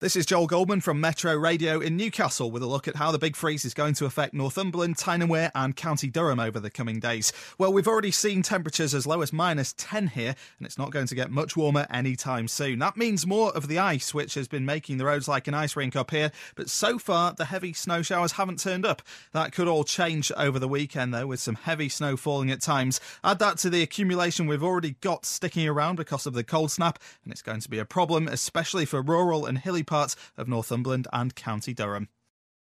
Bad weather update from Metro Radio